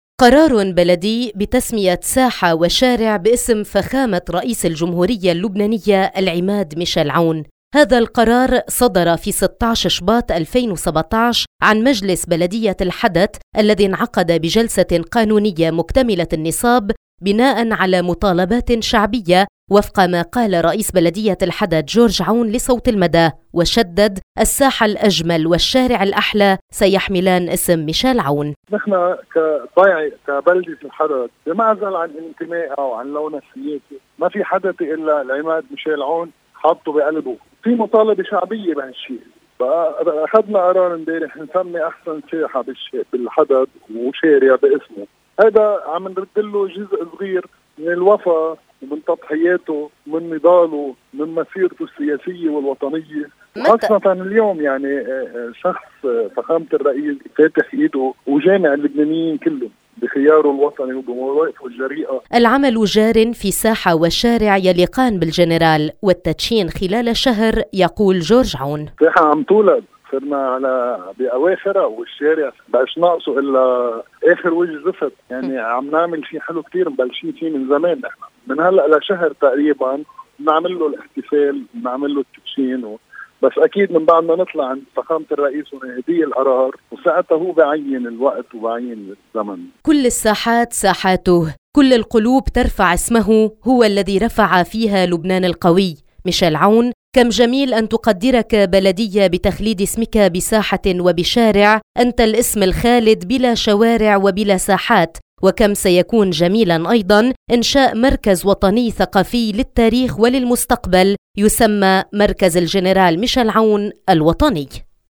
هذا القرار صدر في 16 شباط 2017، عن مجلس بلدية الحدت الذي انعقد بجلسة قانونية مكتملة النصاب بناءً على مطالباتٍ شعبية وفق ما قال رئيس بلدية الحدت جورج عون لصوت المدى، وشدد: الساحة الأجمل والشارع الأحلى سيحملان اسم ميشال عون.